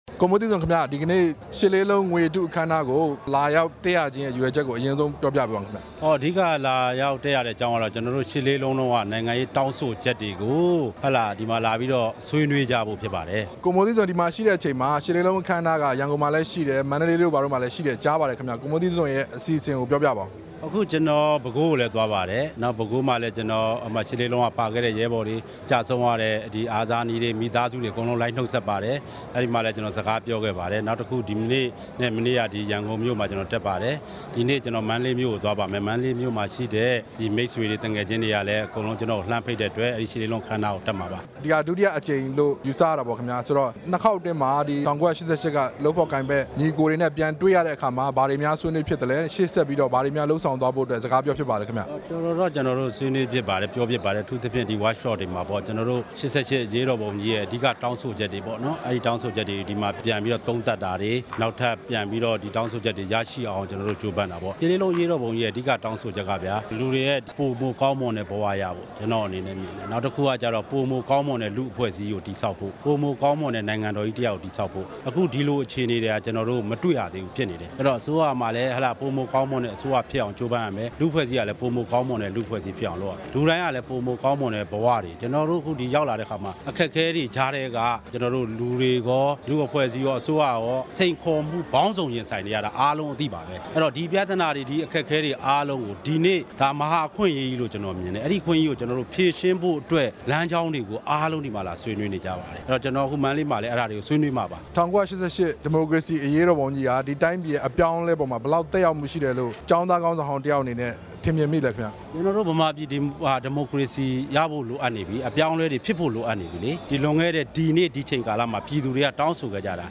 ကျောင်းသားခေါင်းဆောင် ဦးမိုးသီးဇွန်နဲ့ မေးမြန်းချက်